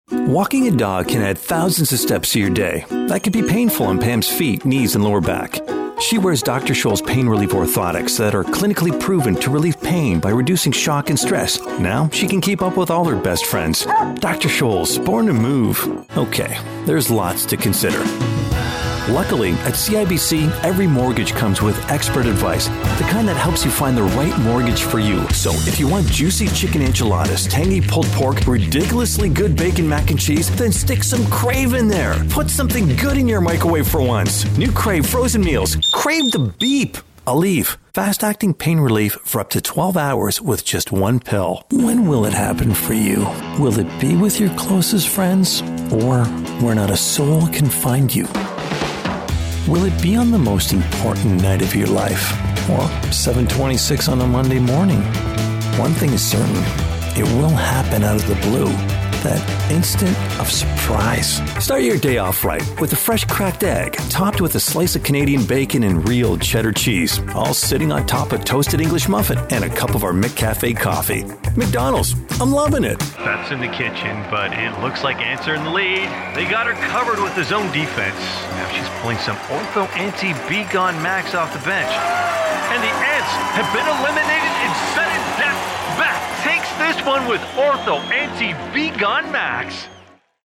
Free voice over demos. Voice overs produced by US and international actors.